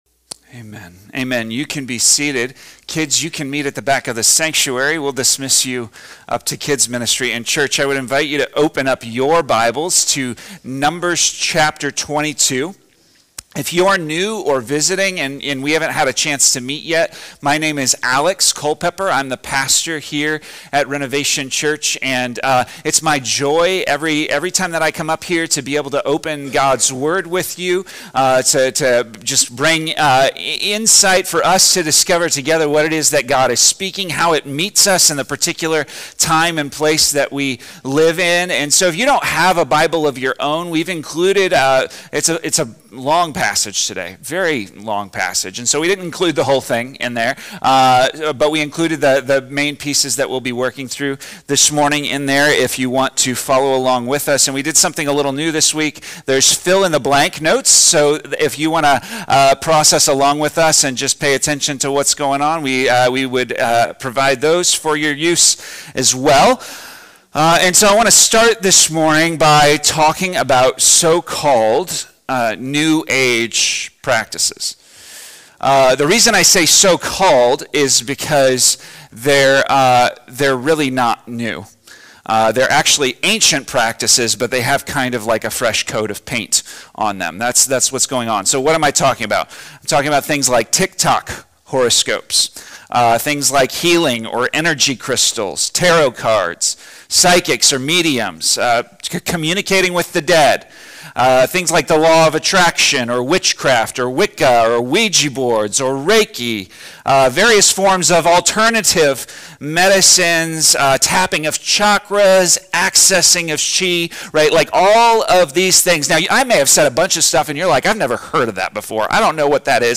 The sermon contrasts Balaam’s manipulative, demonic power with the unstoppable authority of Yahweh, who turns curses into blessings. It’s a call to resist counterfeit spirituality, submit fully to God, and live under the power and protection of His Kingdom.